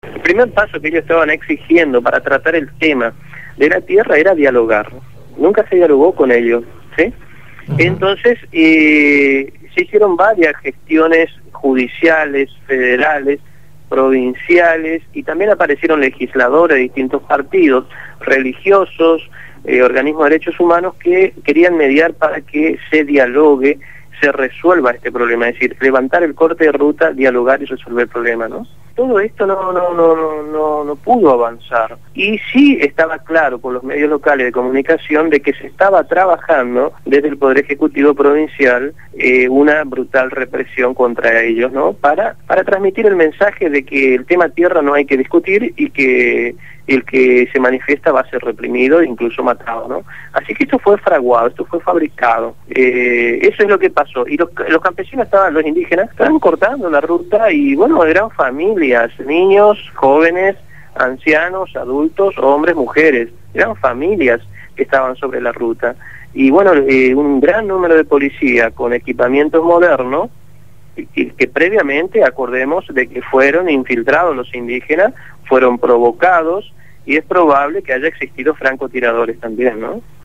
fue entrevistado en «Desde el Barrio»